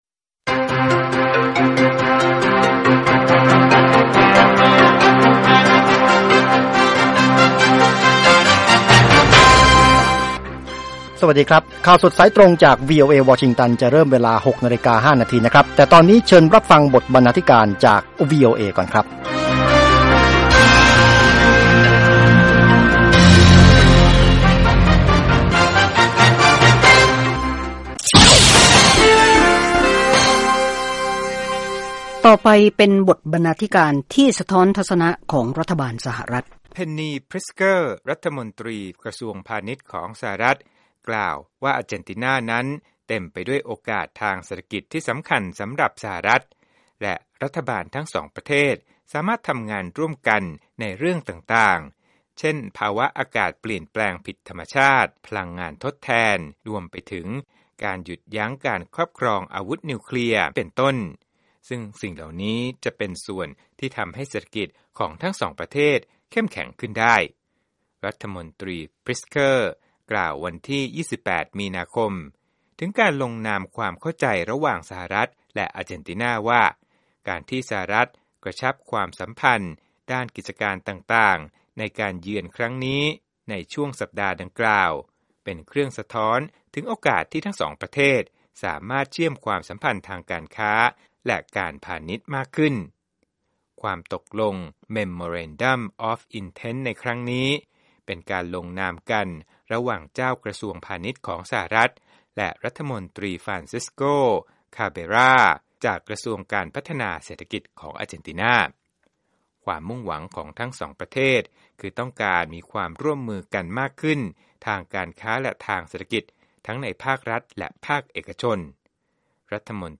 ข่าวสดสายตรงจากวีโอเอ ภาคภาษาไทย 6:00 – 6:30 น. วันพฤหัสบดีที่ 7 เมษายน 2559